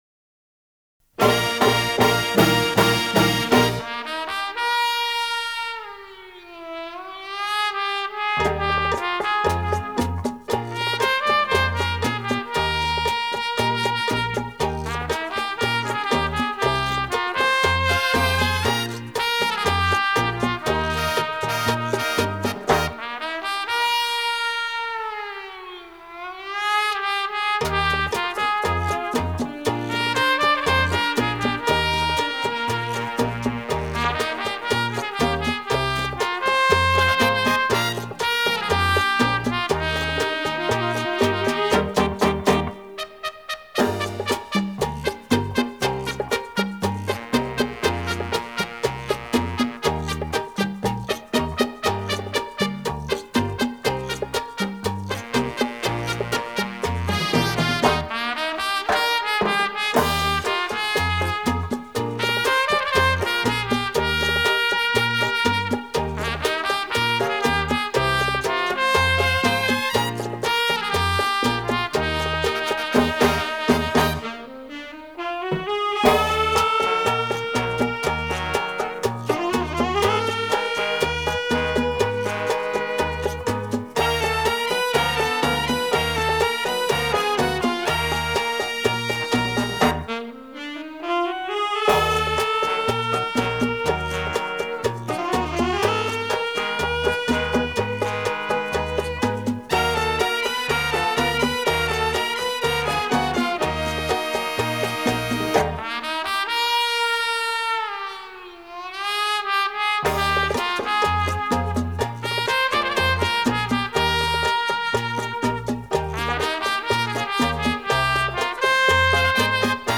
(Cha Cha)
(恰恰)